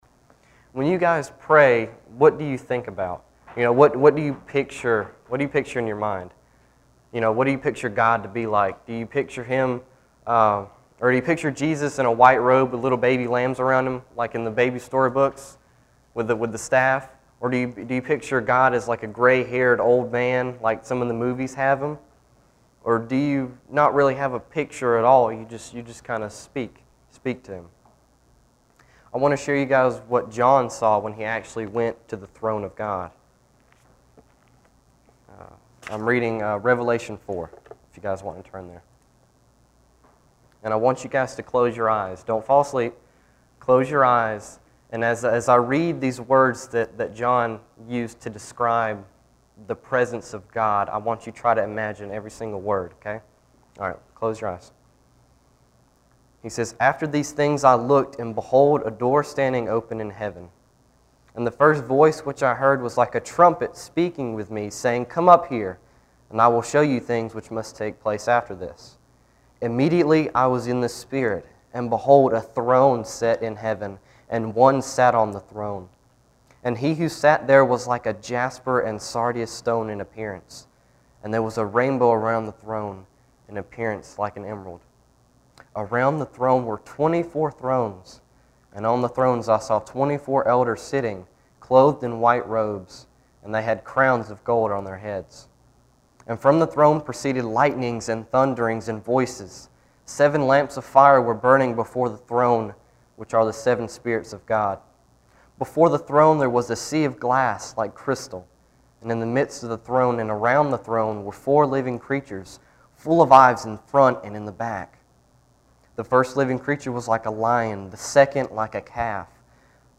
This weeks scripture and sermon: